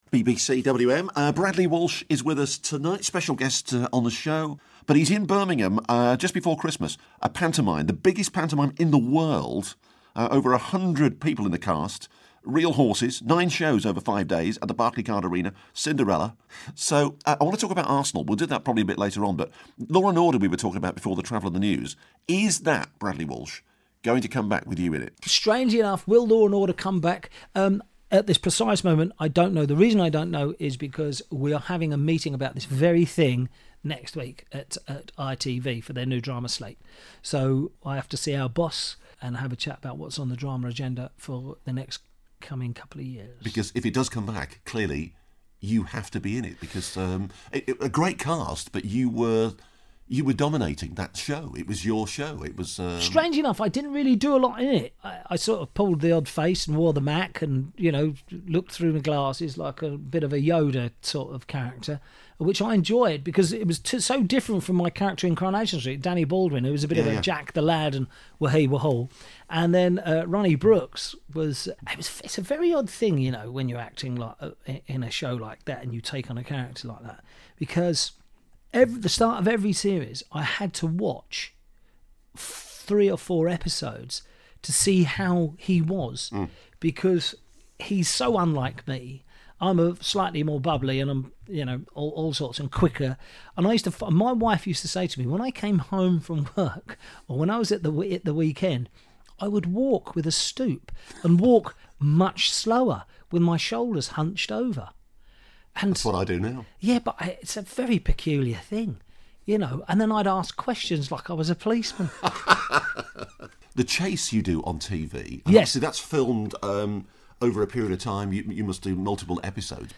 chats to Bradley Walsh